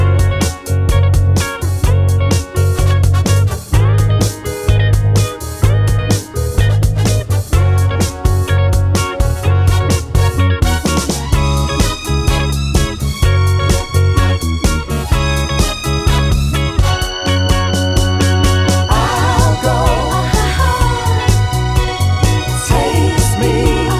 One Semitone Down Soul / Motown 3:22 Buy £1.50